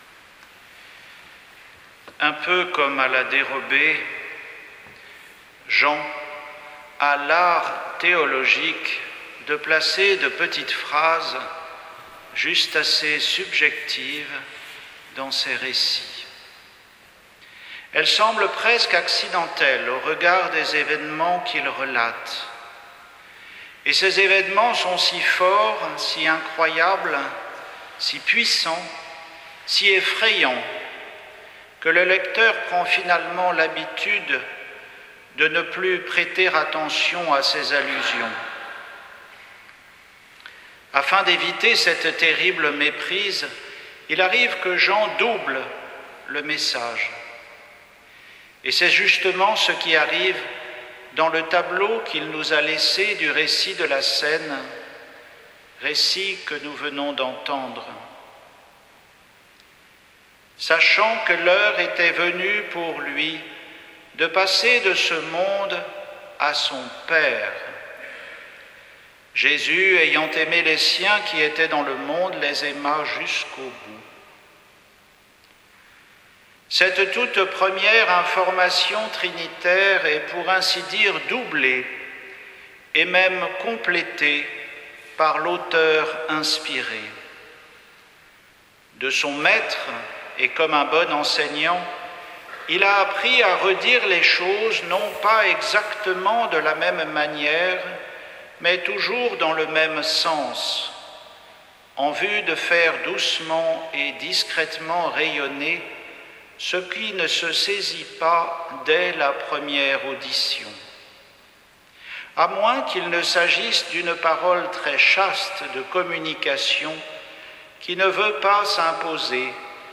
Homélie pour la messe de la Cène du Seigneur, au soir du Jeudi Saint